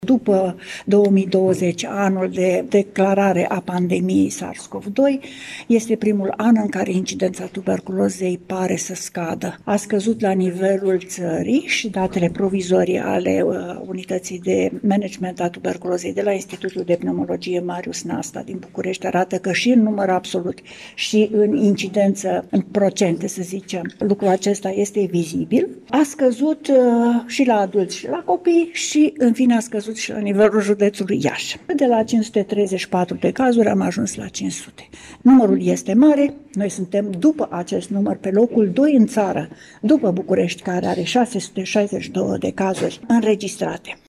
Astăzi, într-o conferință de presă organizată cu prilejul Zilei Mondiale de Luptă împotriva Tuberculozei